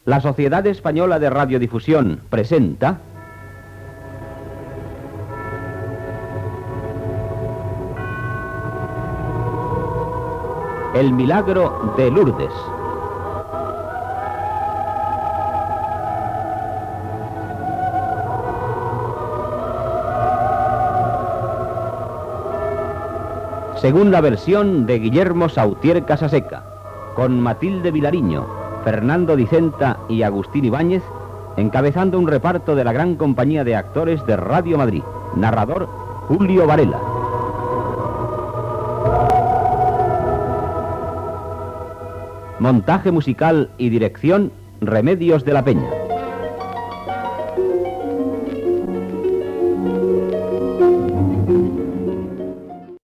Careta del serial radiofònic, amb els crèdits
Ficció